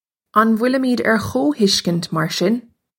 Pronunciation for how to say
Un vwillimidge air ko-hish-kinch, mar shin?
This is an approximate phonetic pronunciation of the phrase.